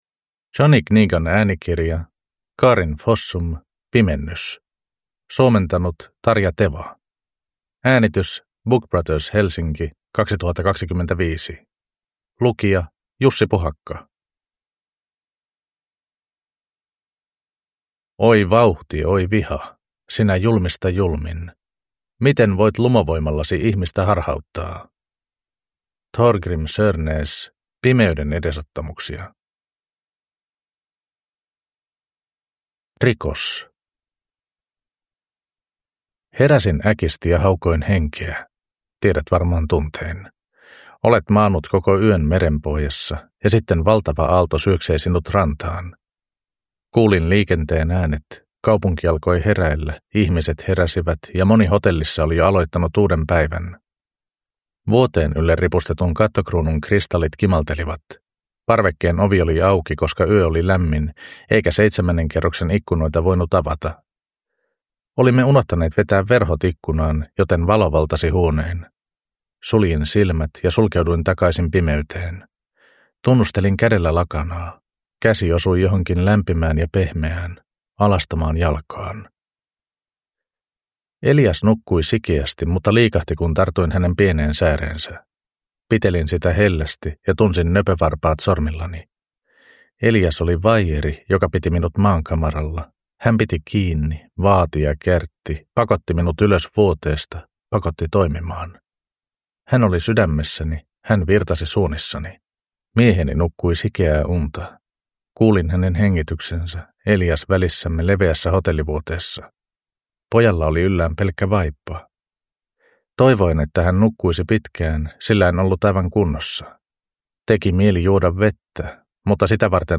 Pimennys (ljudbok) av Karin Fossum